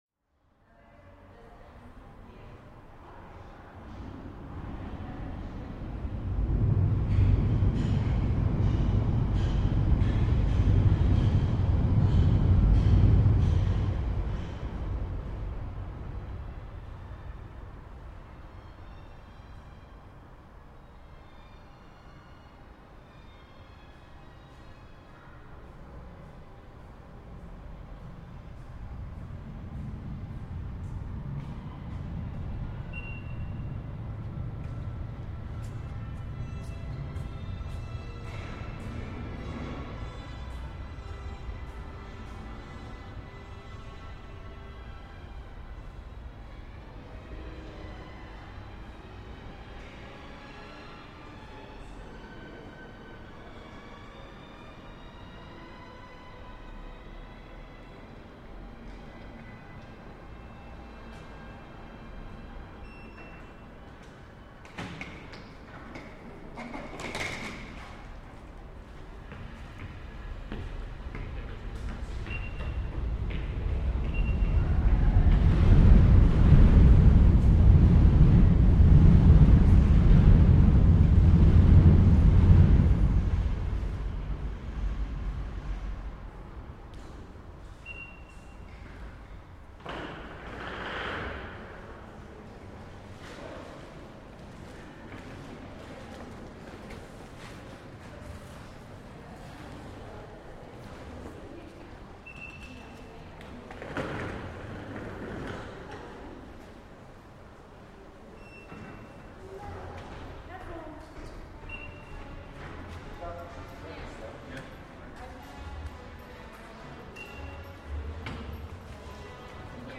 Field recording from the London Underground by London Sound Survey.